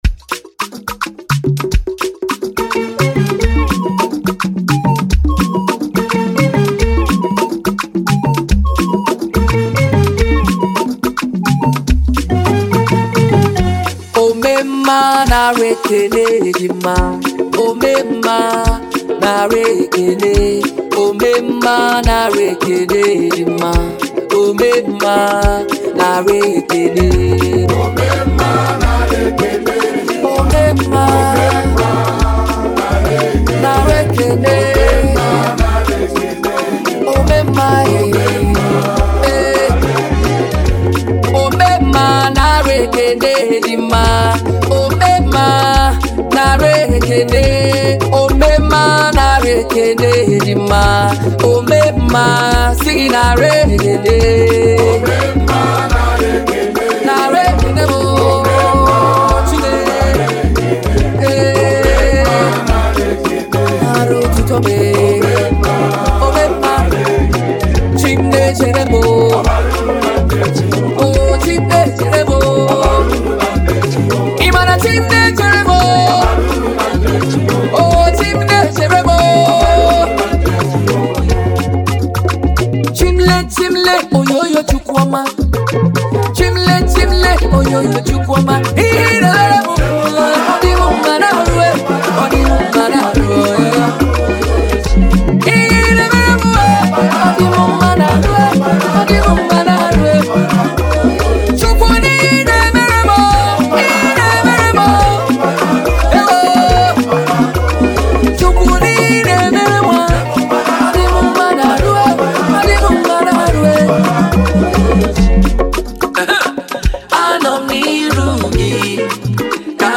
emotionally charged